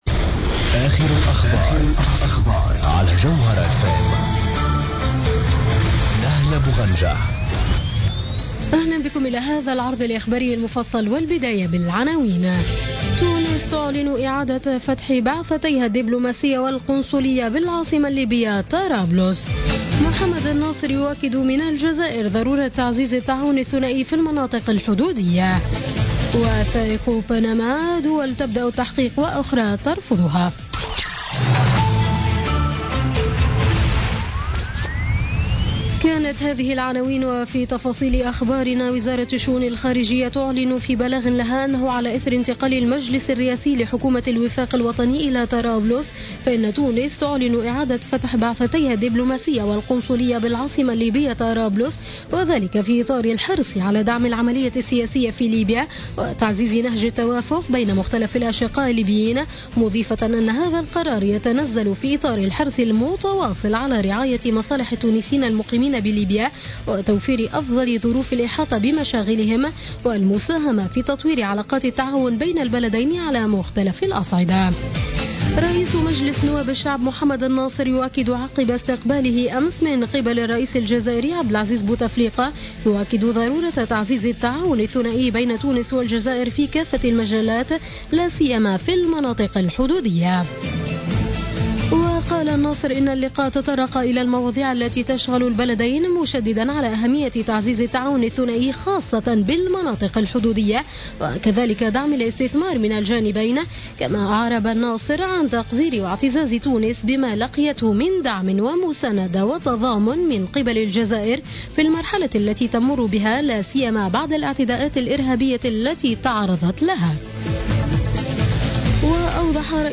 نشرة أخبار منتصف الليل ليوم الثلاثاء 05 أفريل 2016